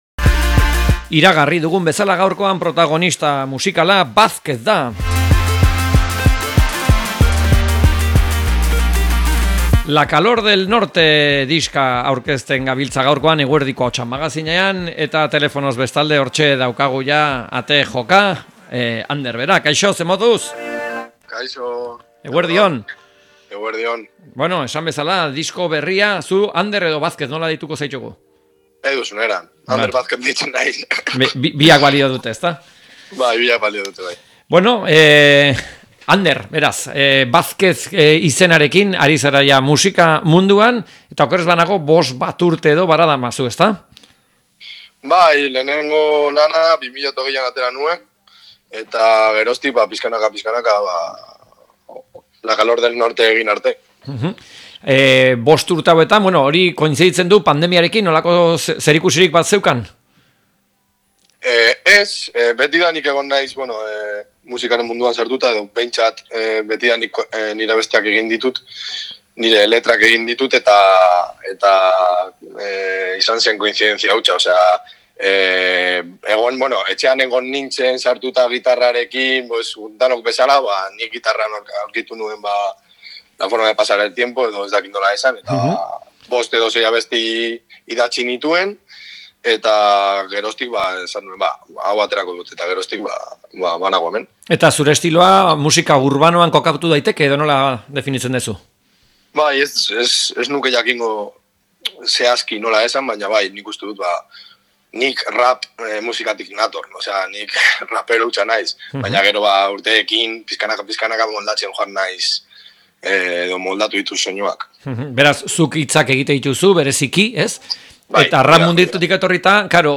elkarrizketa
Elkarrizketak